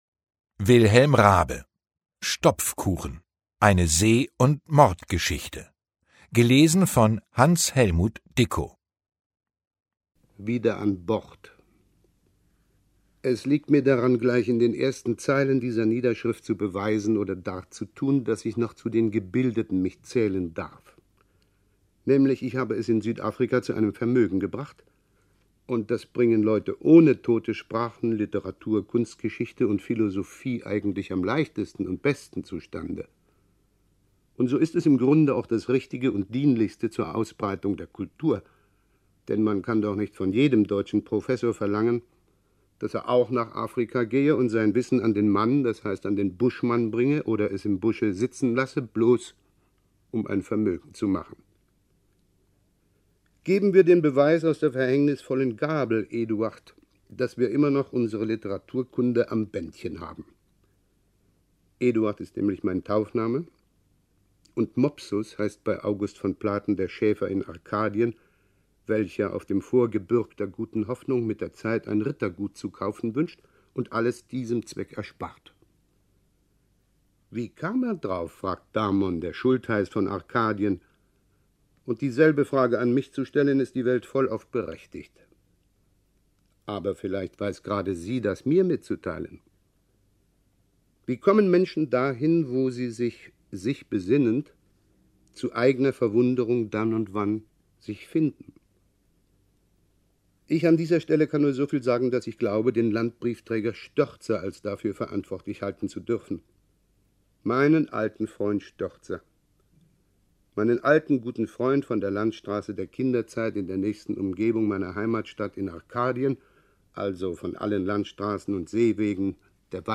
»Die Hörbuch-Edition ›Große Werke. Große Stimmen.‹ umfasst herausragende Lesungen deutschsprachiger Sprecherinnen und Sprecher, die in den Archiven der Rundfunkanstalten schlummern.« SAARLÄNDISCHER RUNDFUNK